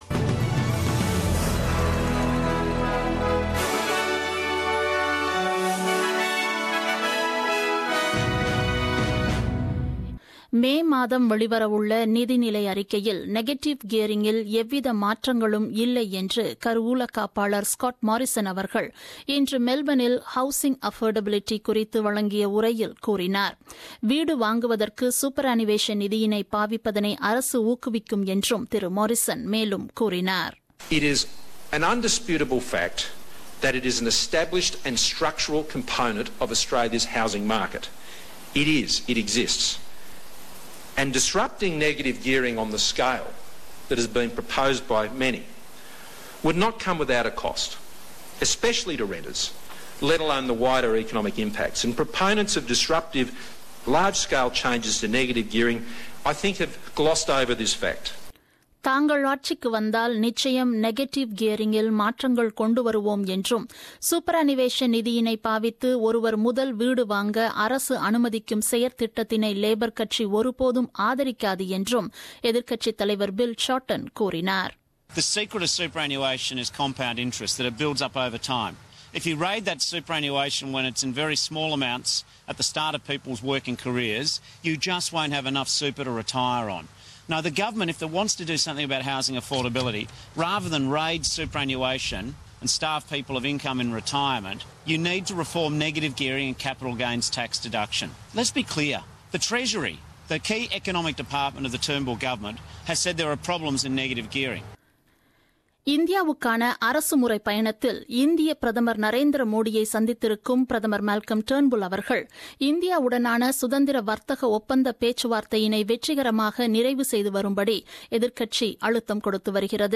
The news bulletin broadcasted on 10th April 2017 at 8pm.